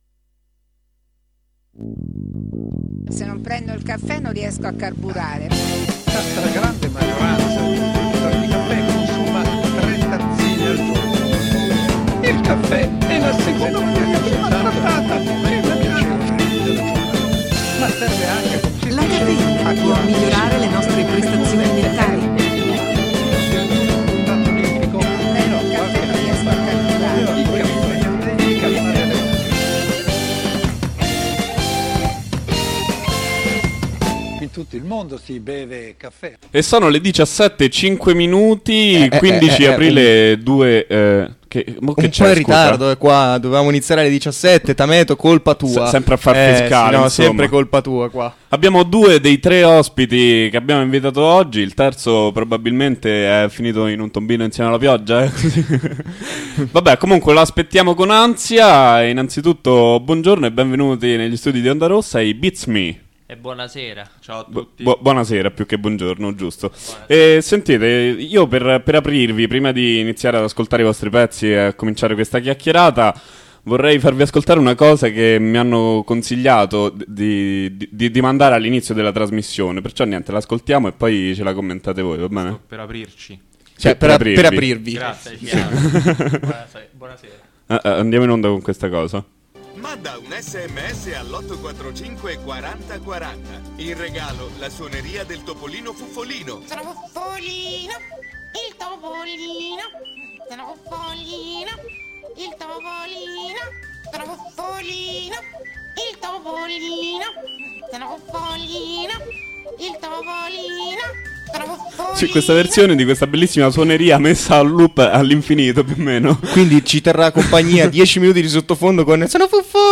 Ospiti in studiio con noi negli studi di Via dei Volsci i "BEATS ME".